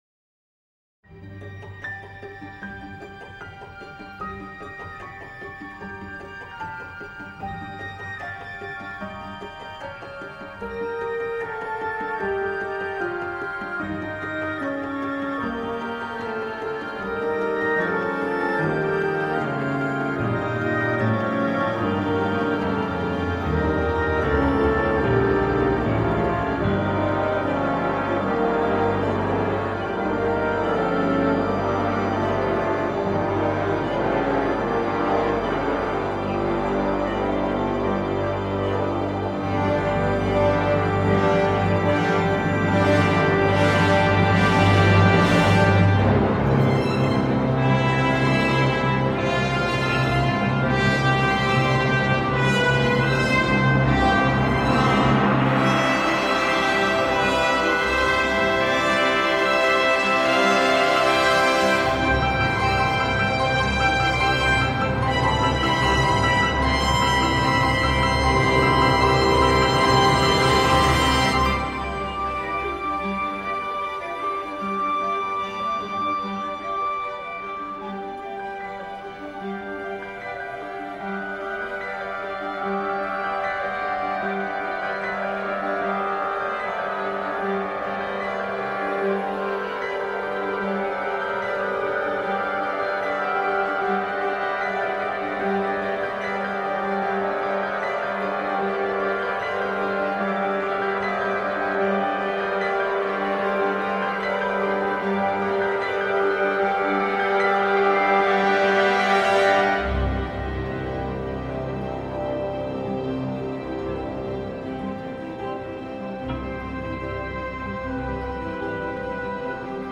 une partition orchestrale tendue
puis massive et même lyrique.